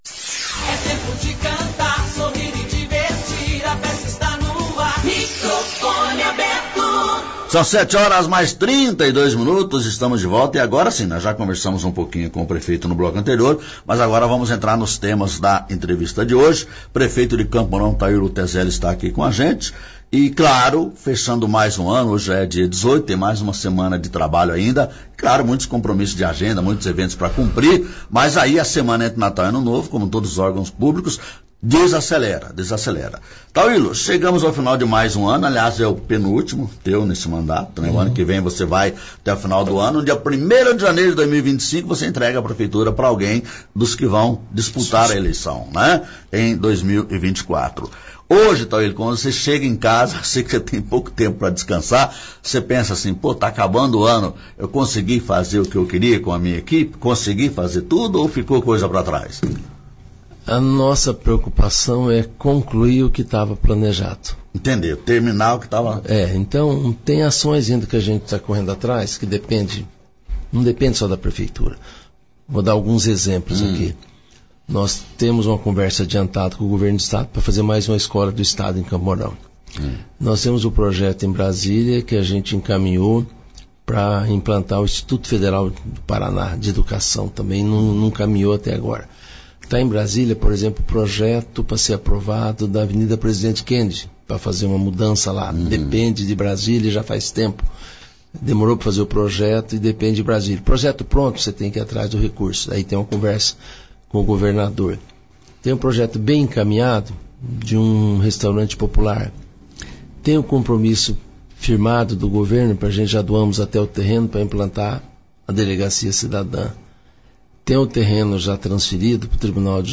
Prefeito Tauillo no programa Microfone Aberto da Rádio Massa FM.